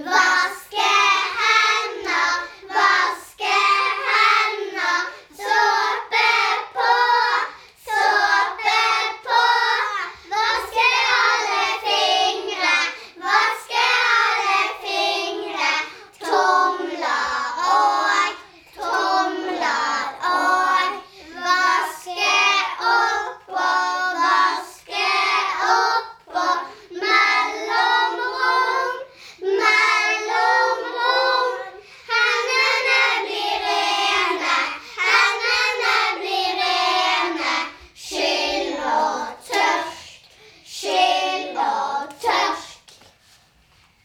Hør barn som synger håndvaskesangen.